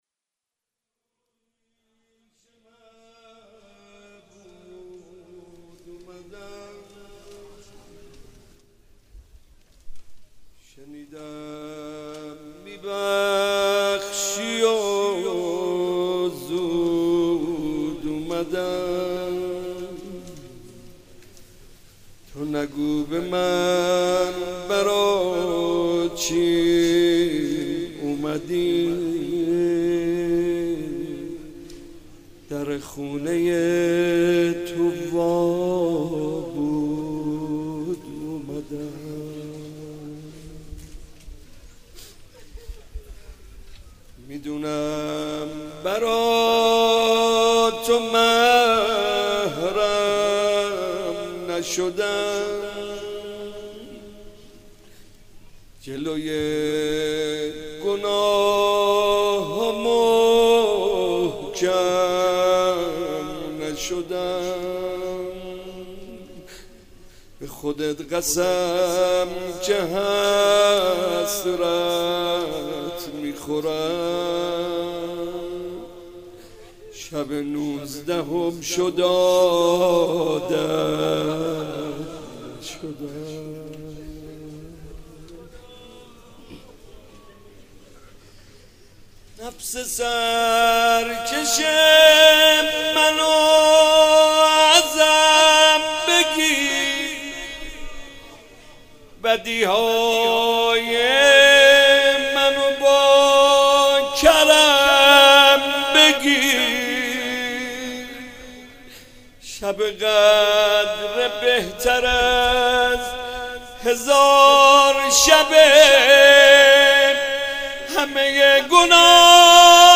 شب نوزدهم رمضان - شب قدر اول
مداح : محمدرضا طاهری
مناجات